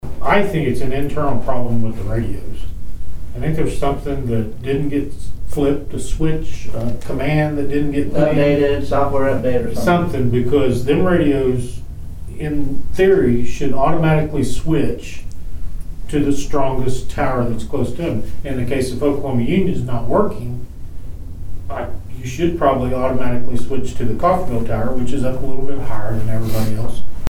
The Nowata County Commissioners had a regularly scheduled meeting on Tuesday morning at the Nowata County Annex.
Nowata County Undersheriff Doug Sonenberg discussed an internal problem with the radios themselves.